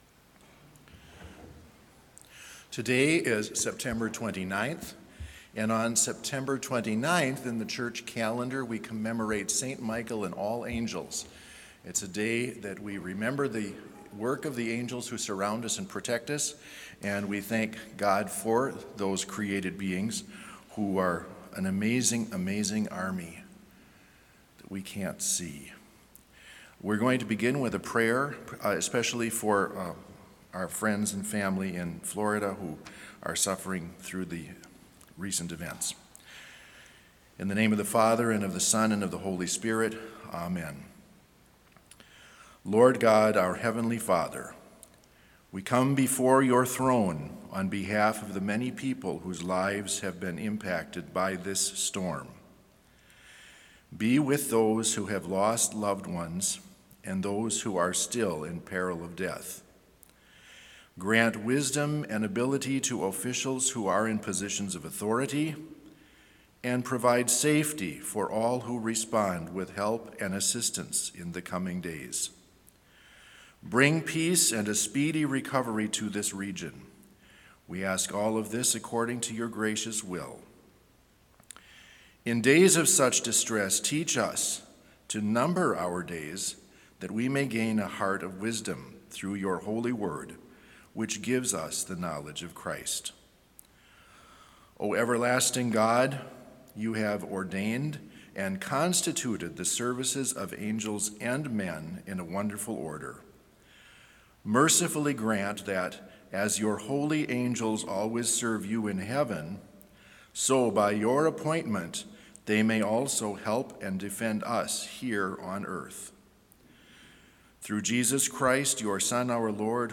Complete service audio for Chapel - September 29, 2022